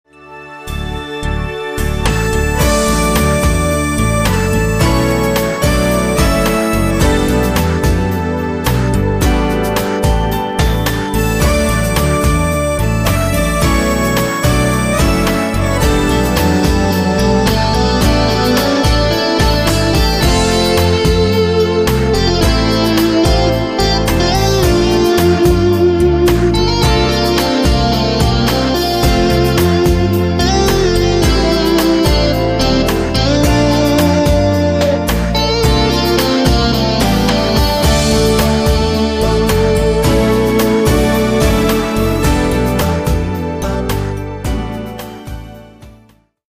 2004 Melodie che inneggiano all’ingenuità e alla tenerezza.